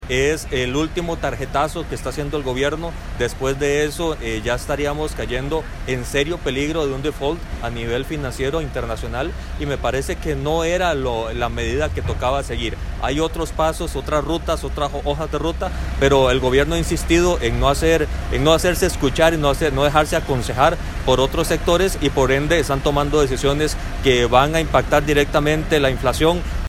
A su vez, el diputado del Partido Restauración Nacional (PRN), Jonathan Prendas, denunció que la medida tomada ayer por parte del Gobierno es temeraria.